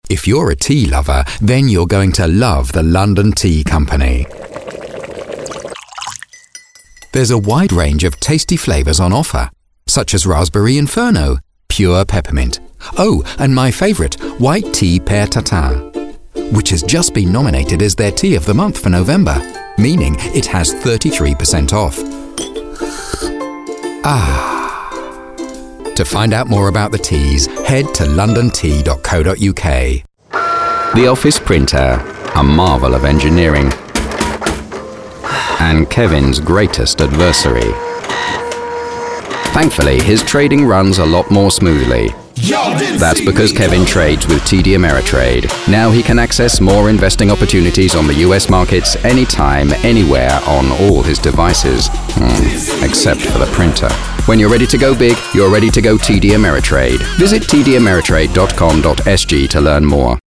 Male
Adult (30-50), Older Sound (50+)
British English, male, warm, mature, assured, rich, friendly, baritone.
Home studio.
Radio Commercials